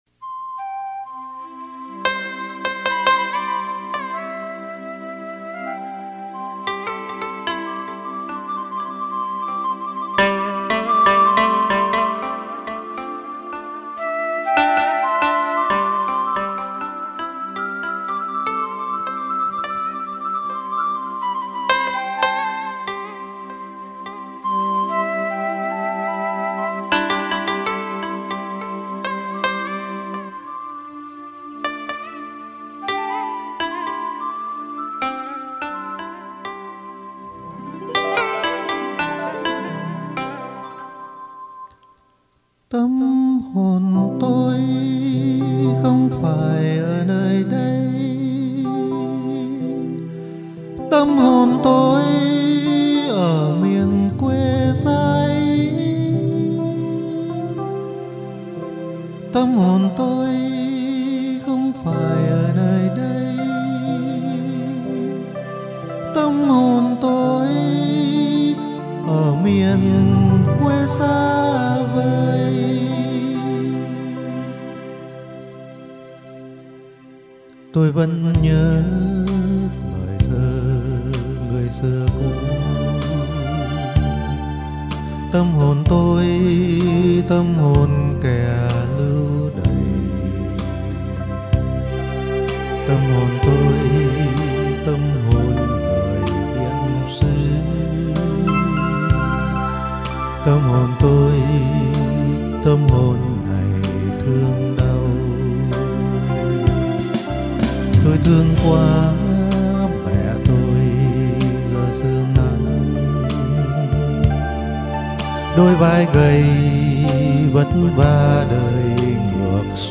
Thú thật ngày đó tôi chưa thấm thía hết cái đẹp của ca từ, mà sự cảm nhận qua âm điệu như vô tình chạm vào vô thức, mặc nhiên giọng hát và tiếng đàn của anh dù là hát cho riêng anh nhưng điều anh không ngờ là tác dụng của nó phần nào giúp cho các bạn đồng tù xua tan đi những cơn ác mộng trong giấc ngủ hằng đêm.